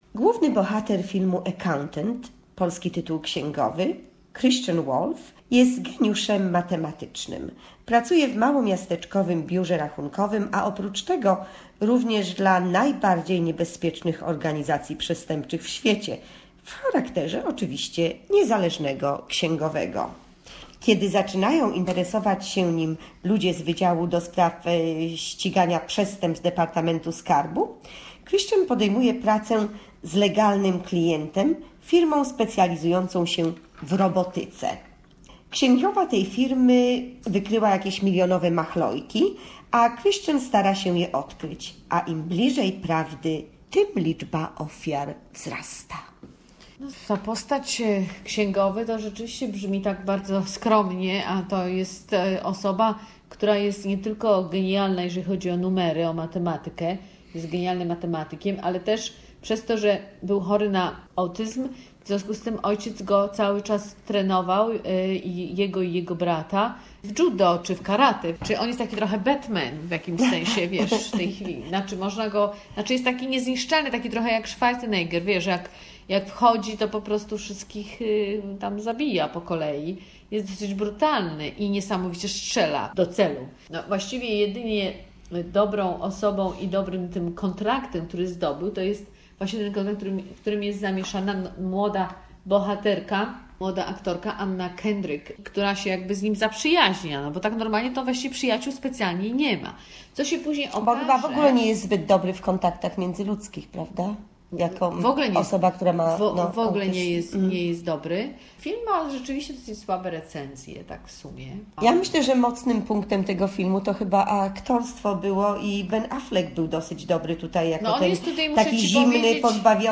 "Księgowy"- recenzja filmowa